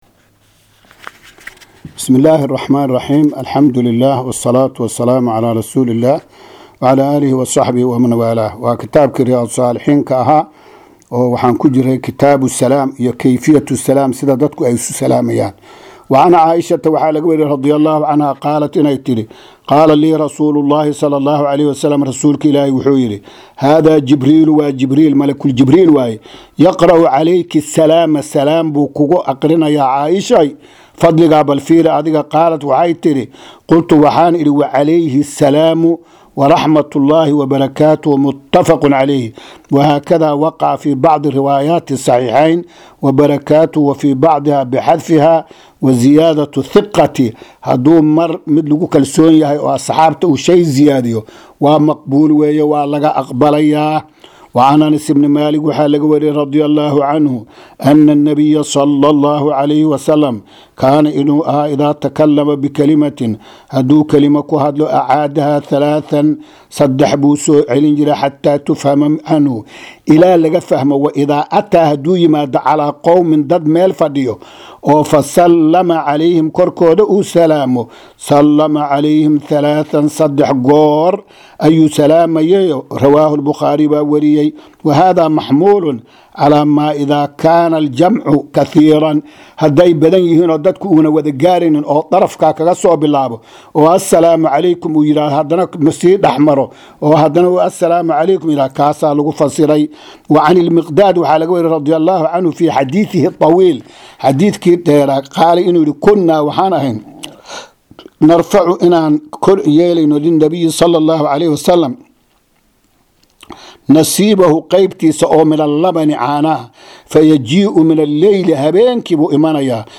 Maqal- Riyaadu Saalixiin – Casharka 47aad